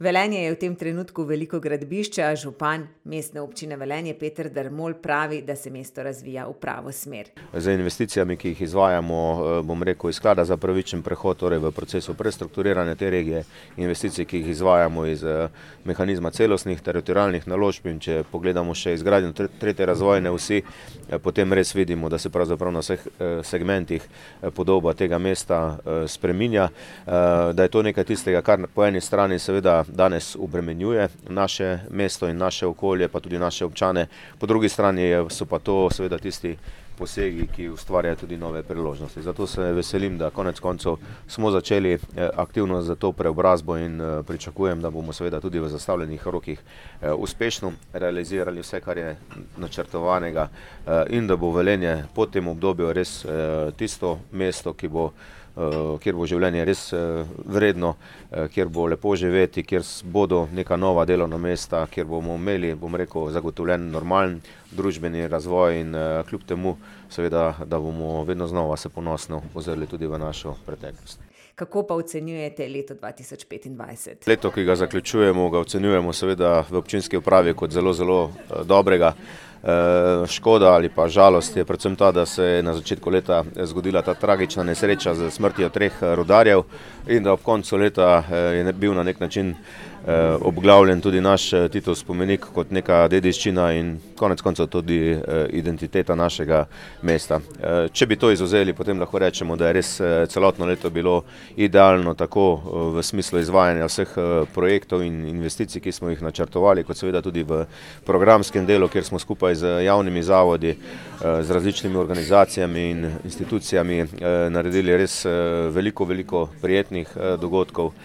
Velenje je gradbišče, a župan MO Velenje Peter Dermol pravi, da se mesto razvija v pravo smer: